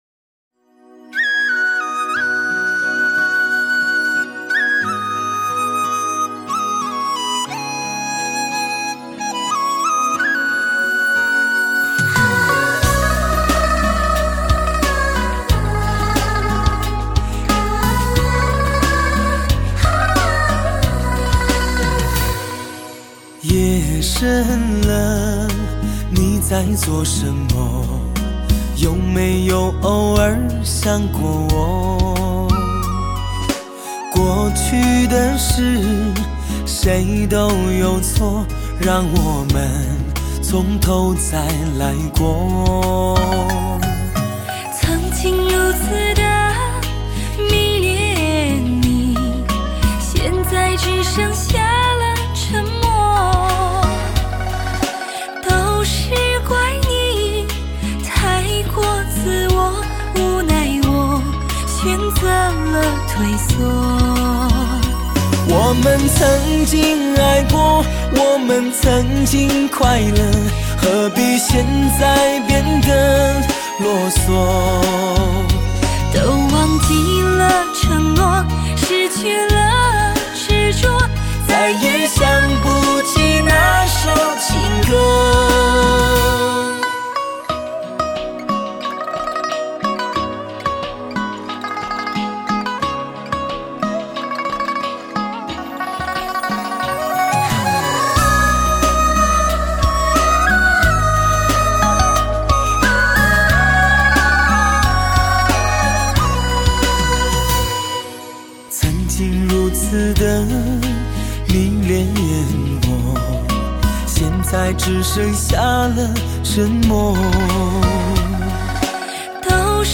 温婉可人
使AQCD的JITTER时基误差被减低四份之一 让唱片的声音更有模拟味道